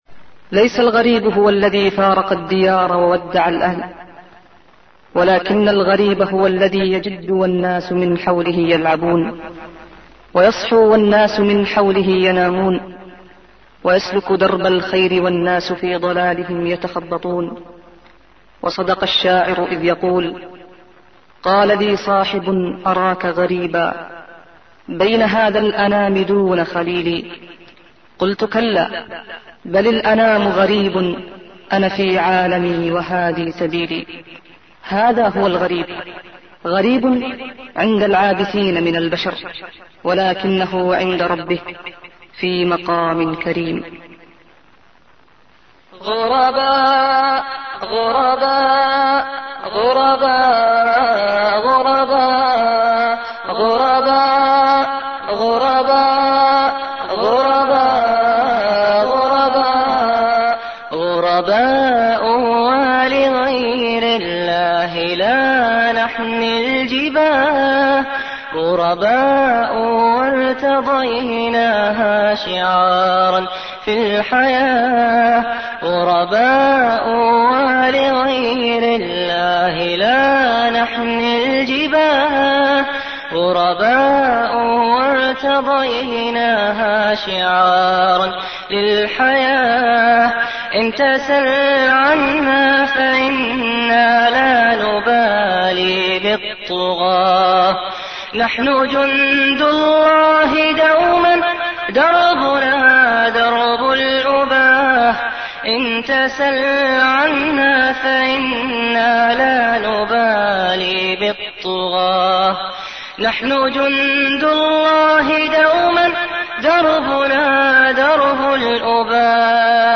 Mp3 Islamic Songs